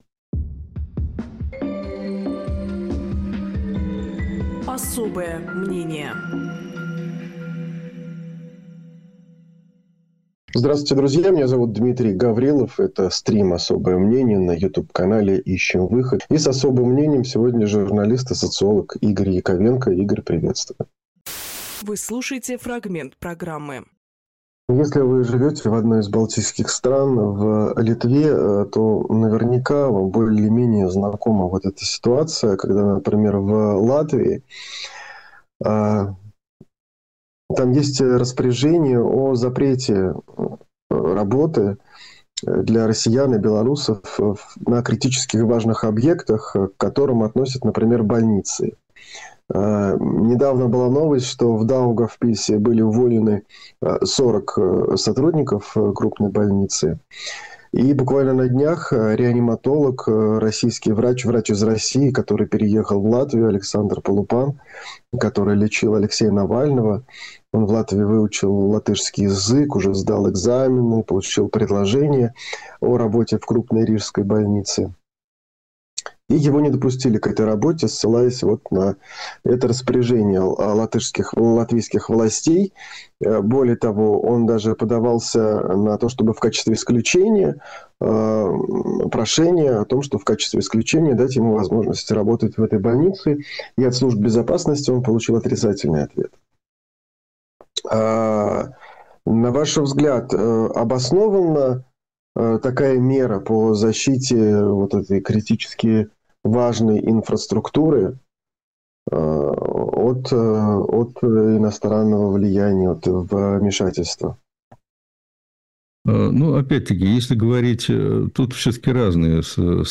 Фрагмент эфира от 12.02.2026